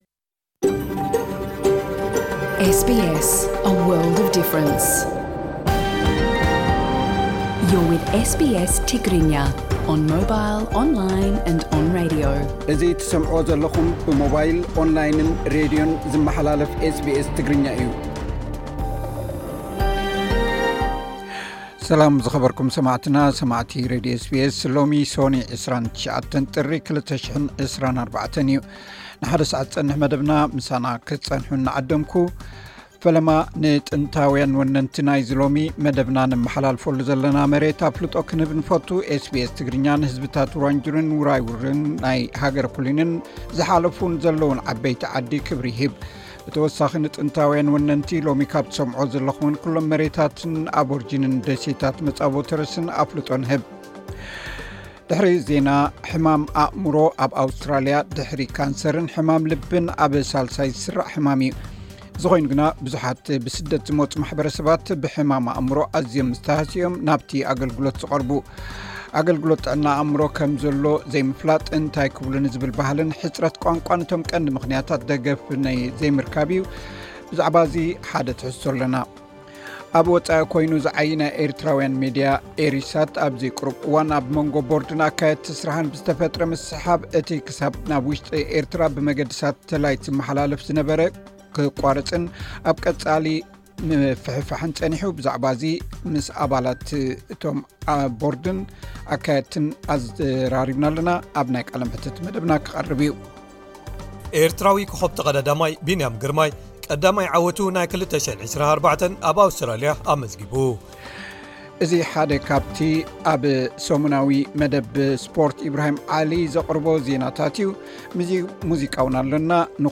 ዝርርብ ኣለና።
ሙዚቃውን ኣለና